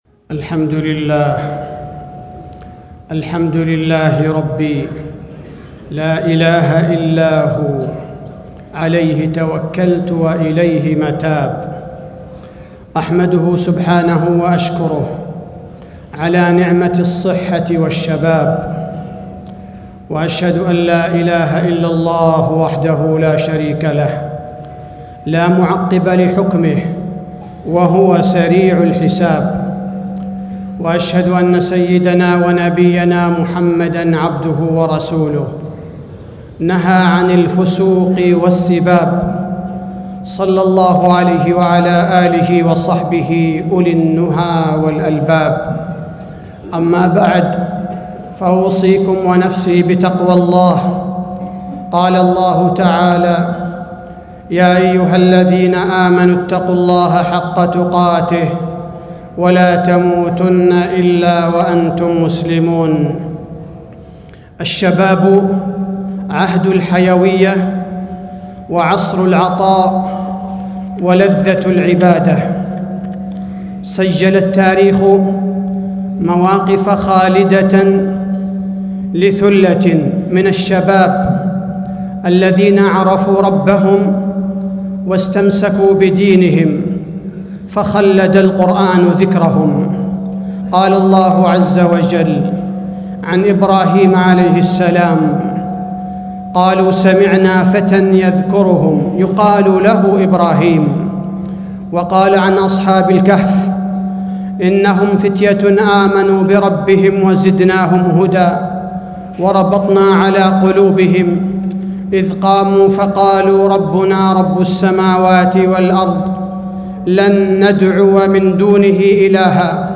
تاريخ النشر ١٥ جمادى الأولى ١٤٣٦ هـ المكان: المسجد النبوي الشيخ: فضيلة الشيخ عبدالباري الثبيتي فضيلة الشيخ عبدالباري الثبيتي الشباب عدة الأمل